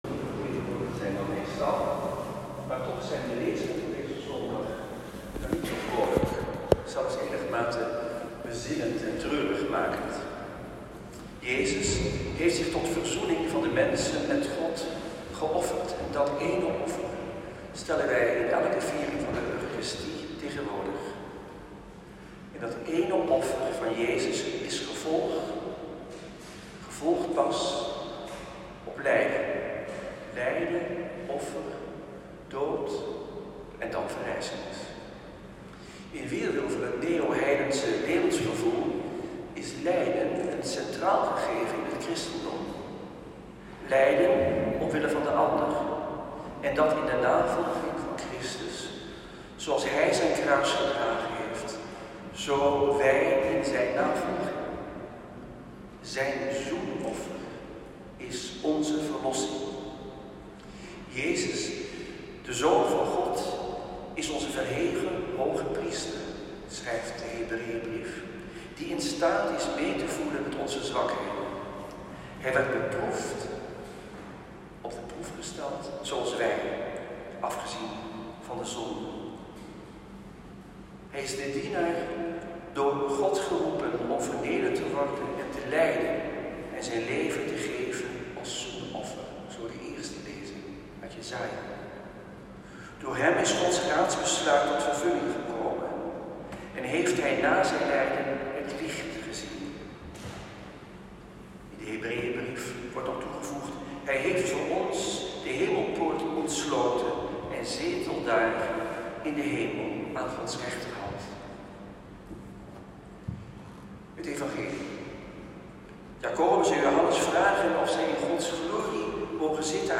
Negenentwintigste Zondag door het jaar. Celebrant Antoine Bodar.
Preek-2.m4a